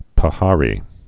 (pə-härē)